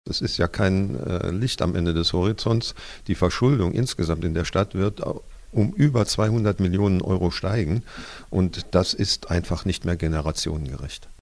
Ein Teufelskreis und eigentlich unverantwortlich, so der Kämmerer im Radio Siegen-Interview.